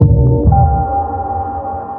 sonarPingAirCloseShuttle1.ogg